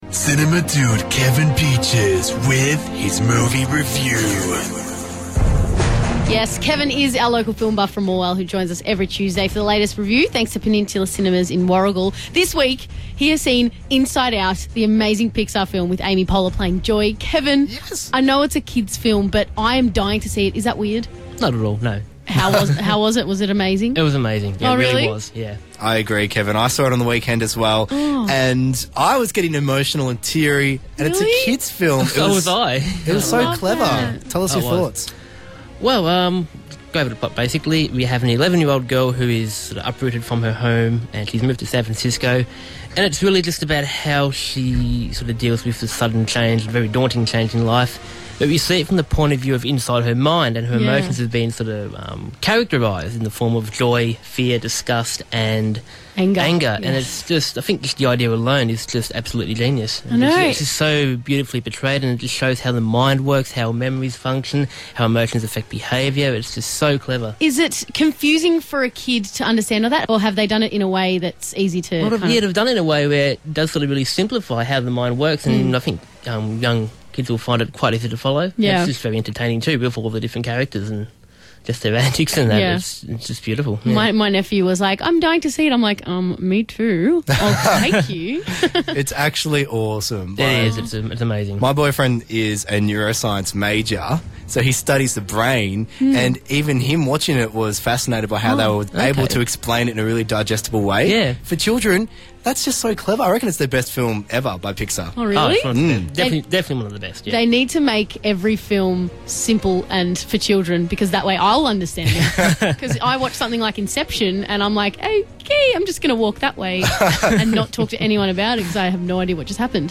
Reviewed on Star FM Gippsland